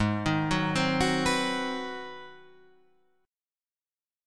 Ab7#9 chord